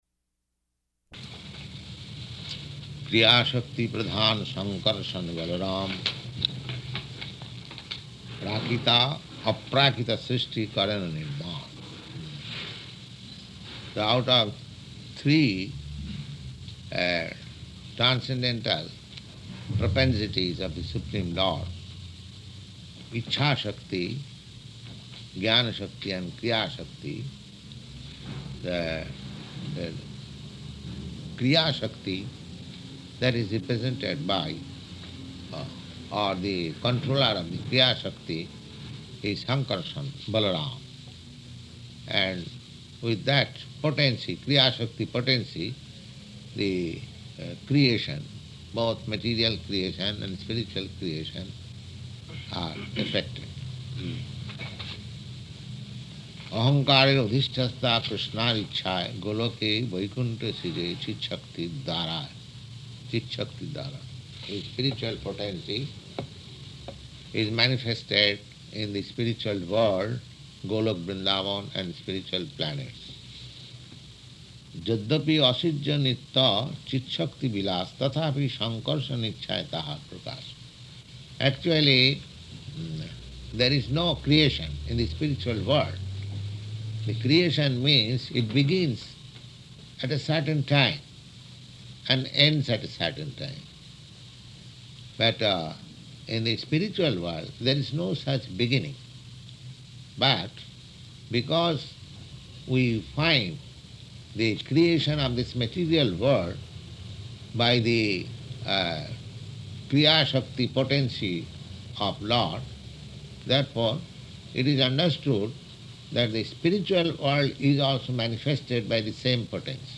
Type: Caitanya-caritamrta
Location: New York